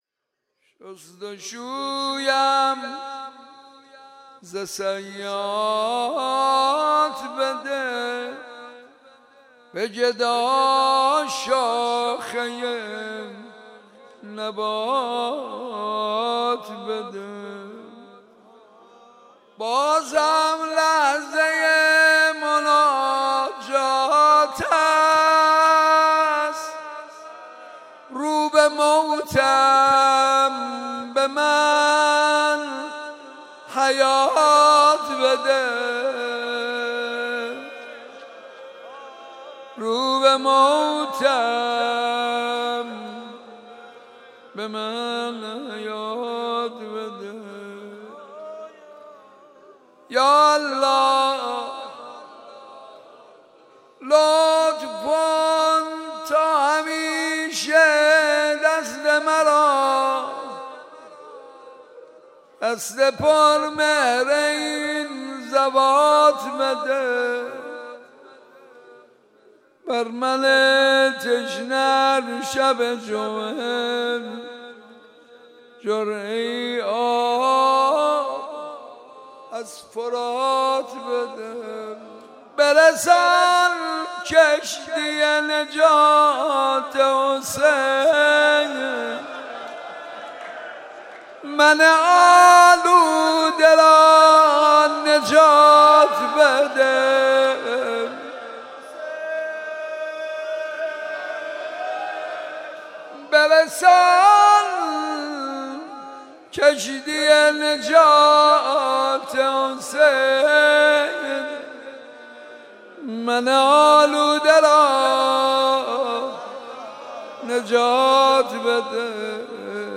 مناجات شست‌شویم ز سیئات بده با صدای حاج منصور ارضی